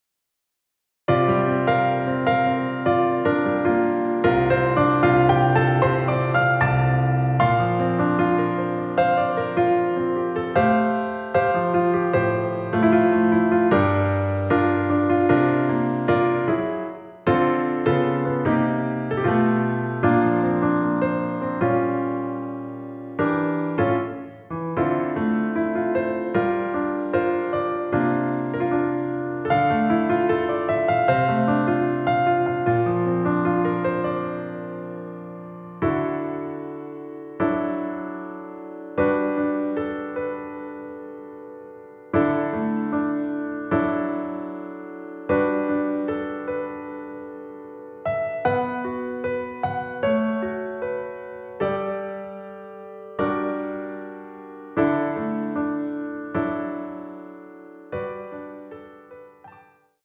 처음 시작을 후렴구로 시작을 하고 앞부분 “드릴것이 없었기에 ~ 기억도 나지 않네요” 삭제된 편곡 입니다.
원키에서(+6)올린 MR이며 멜로디 MR 미리듣기와 본문의 가사를 참고 하세요~
앞부분30초, 뒷부분30초씩 편집해서 올려 드리고 있습니다.